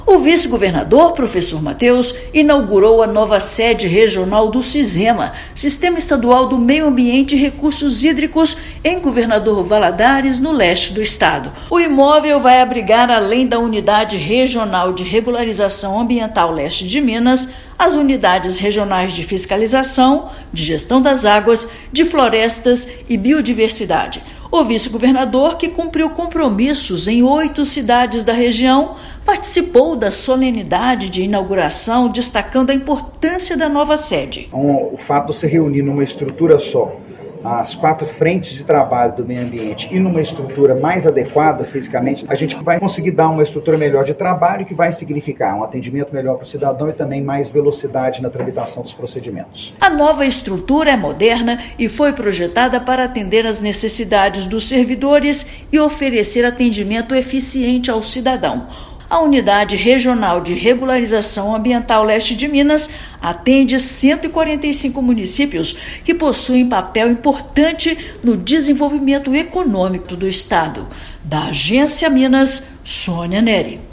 Instalada em Governador Valadares, estrutura foi projetada para garantir atendimento eficiente aos cidadãos e atender às necessidades dos servidores. Ouça matéria de rádio.